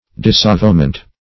Disavowment \Dis`a*vow"ment\, n.